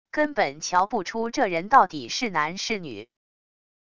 根本瞧不出这人到底是男是女wav音频生成系统WAV Audio Player